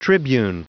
Prononciation du mot tribune en anglais (fichier audio)
Prononciation du mot : tribune